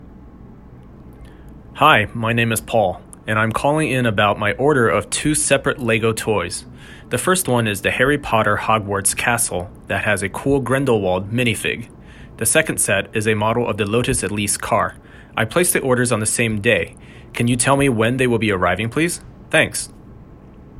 sample audio and stored it in an S3 bucket (this is a pre-requisite and can be achieved by following documentation).